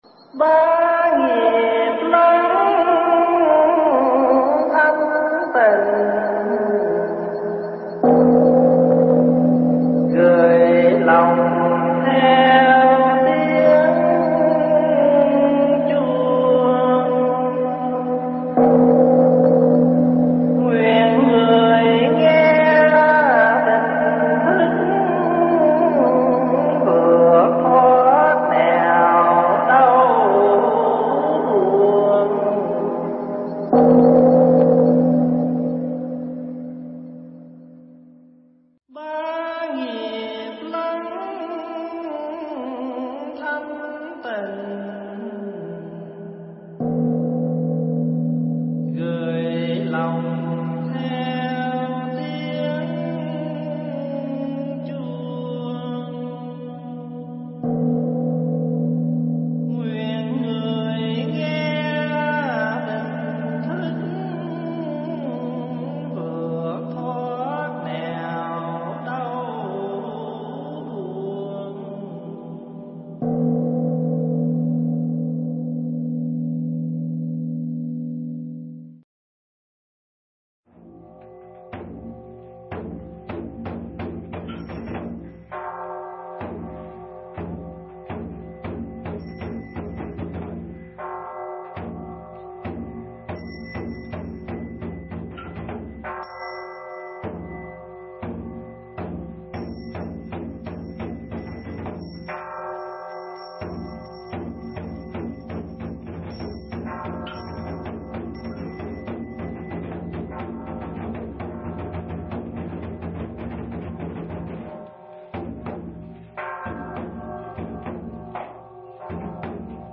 Nghe Mp3 thuyết pháp Biển Phước Chứa Vô Cùng
thuyết giảng tại Chùa Phước Hải